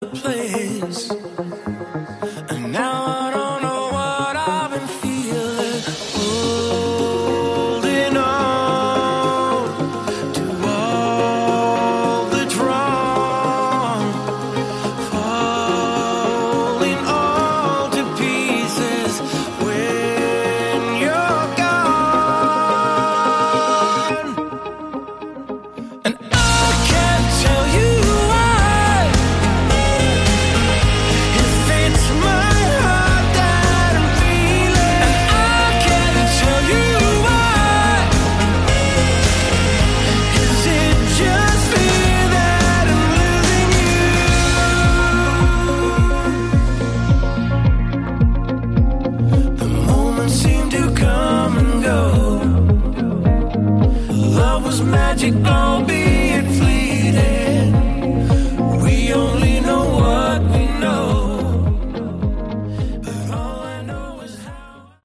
Category: Light AOR
saxophone
keyboards, vocals
guitar, vocals
drums
bass